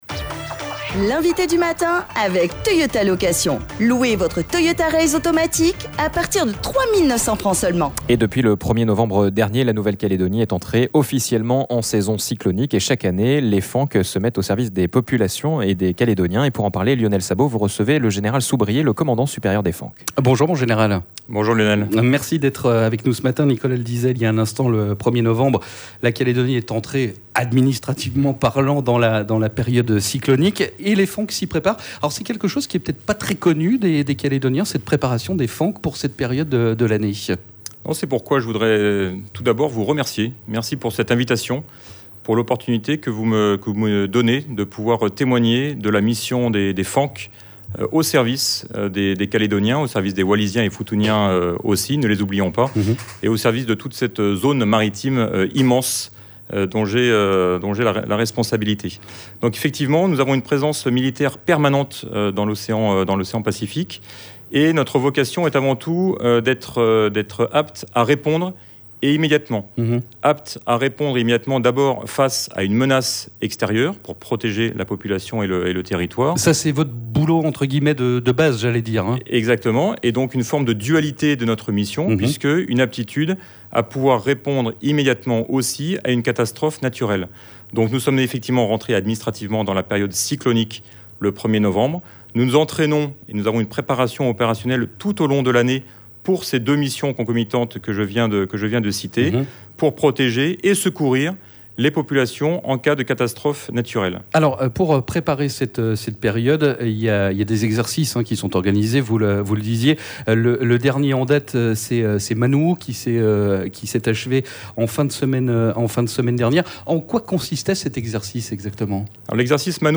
Les FANC au service de la population pour cette période particulière. Nous en parlons avec le Général de Brigade Gabriel Soubrier, le Commandant Supérieur des Forces Armées en Nouvelle-Calédonie.